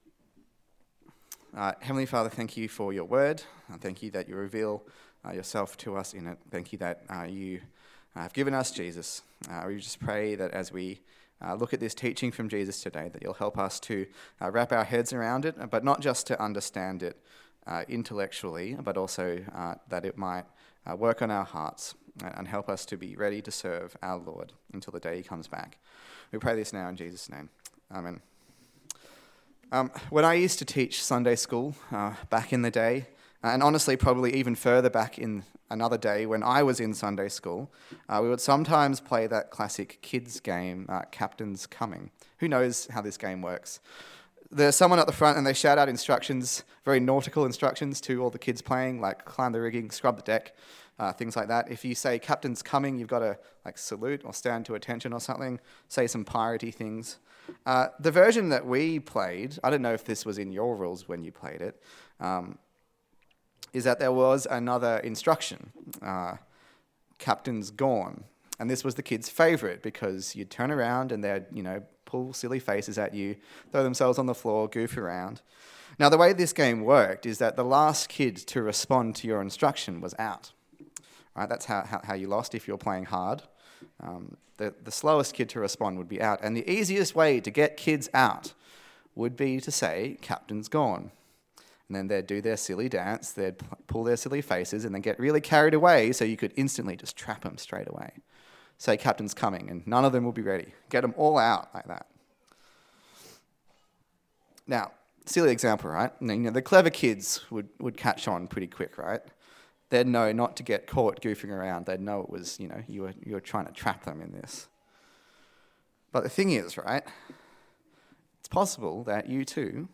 Luke Passage: Luke 12:35-48 Service Type: Sunday Service